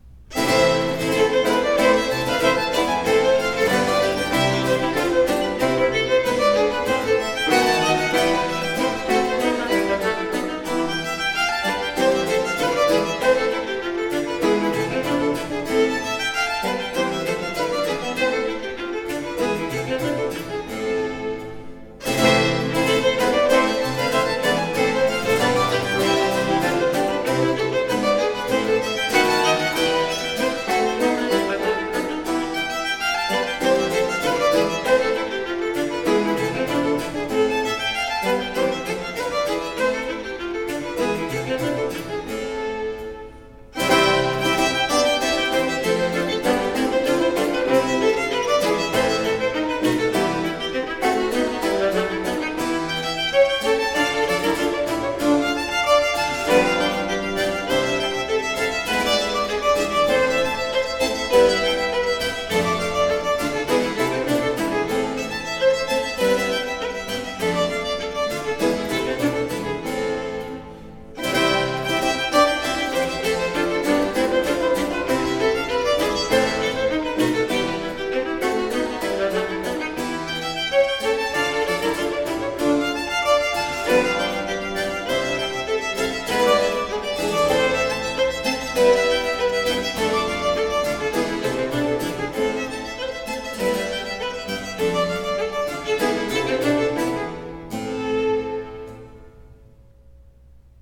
presto